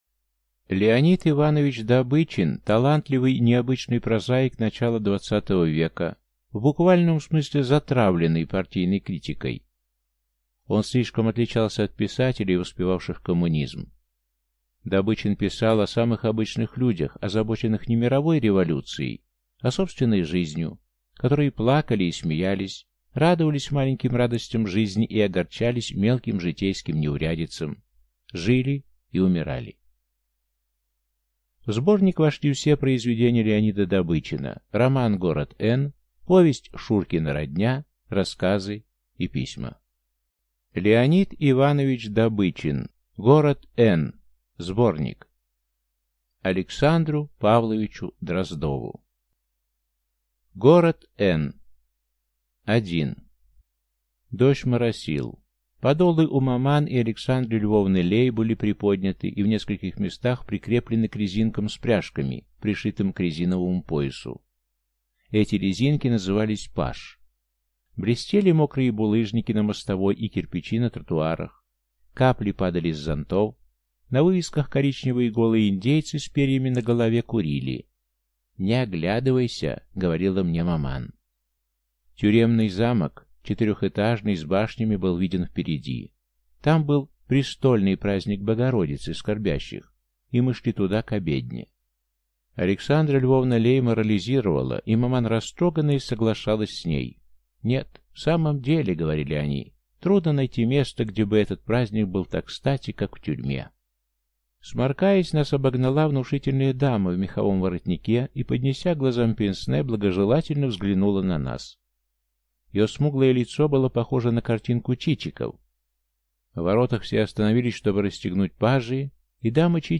Аудиокнига Город Эн (сборник) | Библиотека аудиокниг